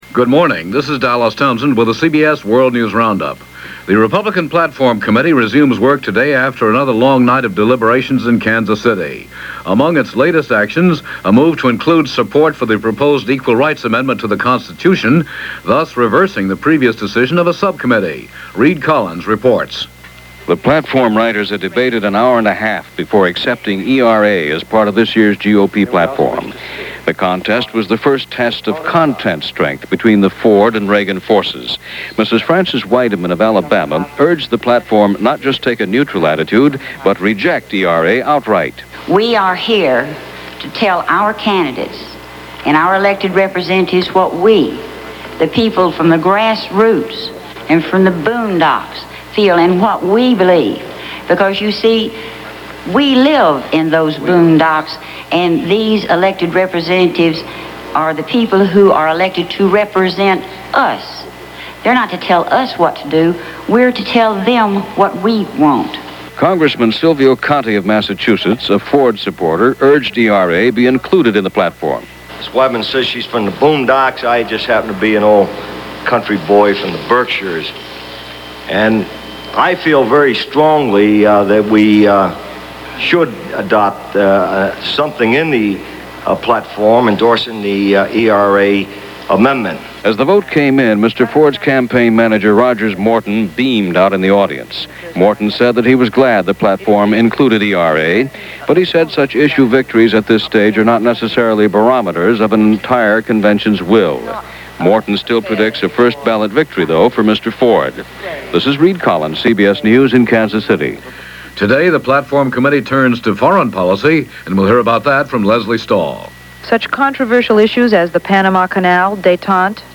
And that’s just a small slice of what happened, this August 12, 1976 as reported by The CBS World News Roundup.